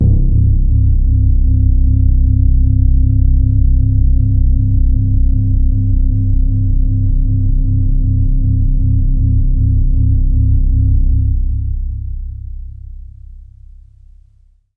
描述：这是我的Q Rack硬件合成器的一个样本。
低通滤波器使声音变得圆润而柔和。
在较高的区域，声音变得非常柔和，在归一化之后，一些噪音变得很明显。
Tag: 重低音 电子 醇厚 多样品 柔软 合成器 华尔